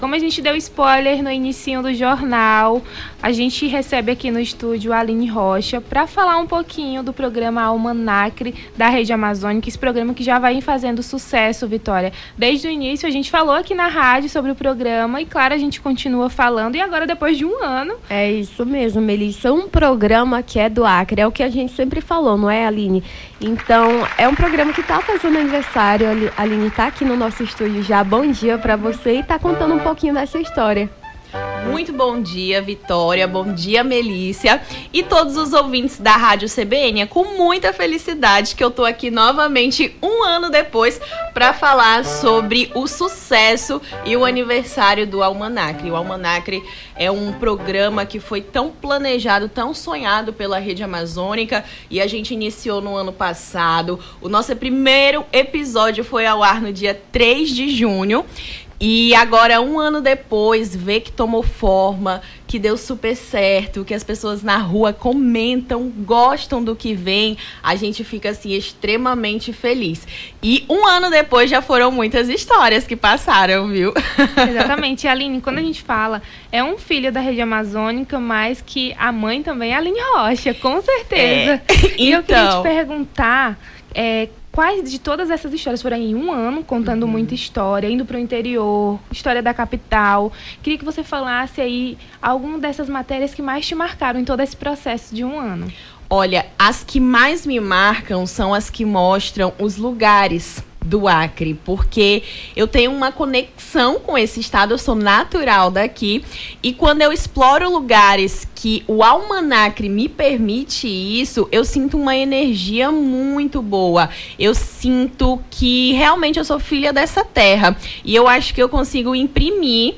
Nome do Artista - CENSURA - ENTREVISTA ALMANACRE 1 ANO (31-05-24).mp3